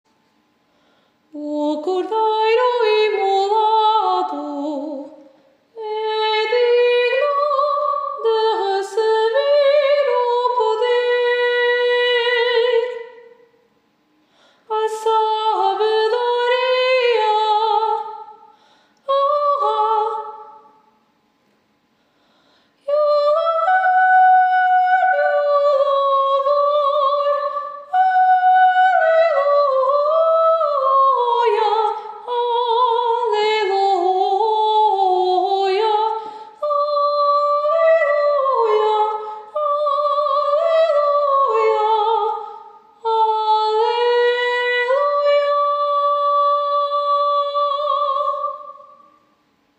Soprano